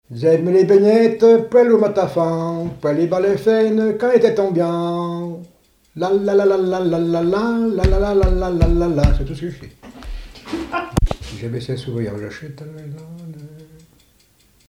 Usage d'après l'informateur gestuel : danse
Genre brève
Pièce musicale inédite